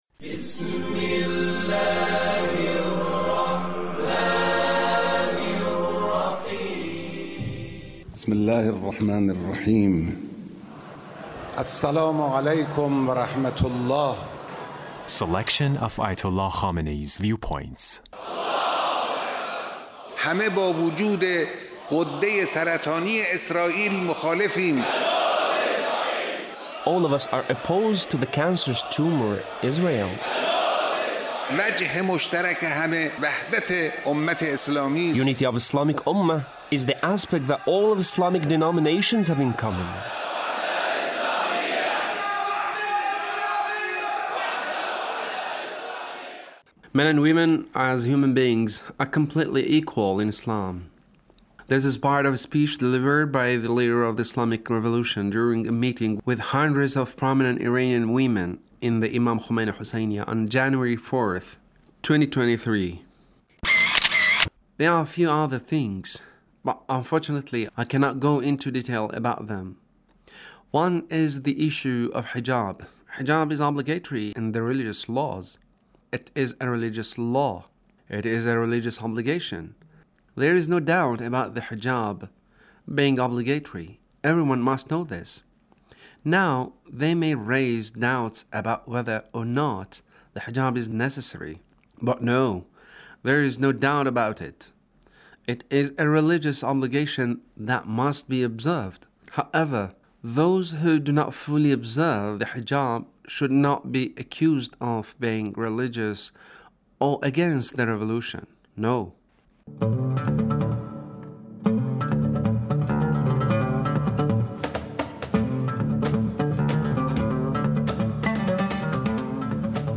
Leader's Speech meeting with ladies